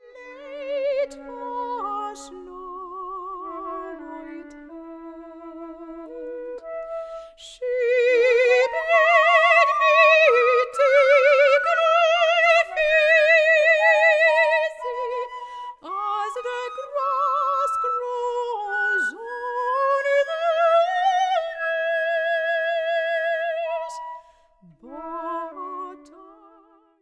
Portuguese Soprano
CONTEMPORARY IRISH SONGS - flute